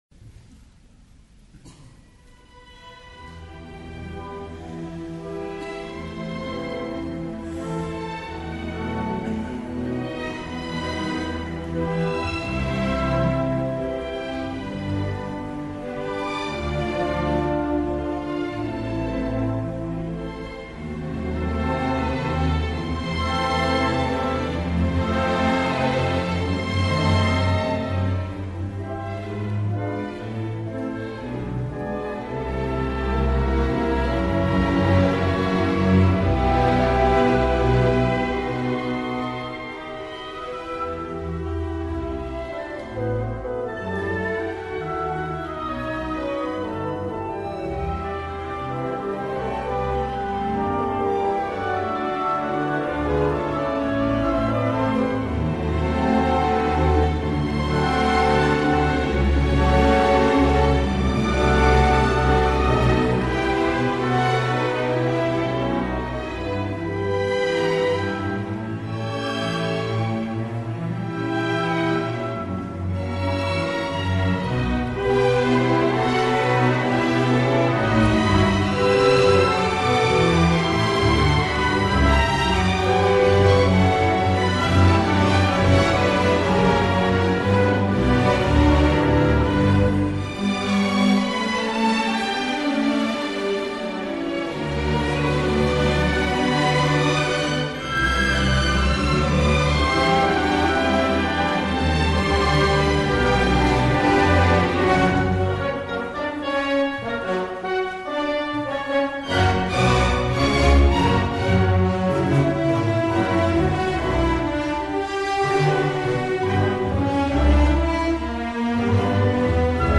Grabación realizada por el equipo de exteriores de las radios públicas el 26 de julio en el Auditorio Nacional Adela Reta.